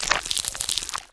rifle_eyestalk2.wav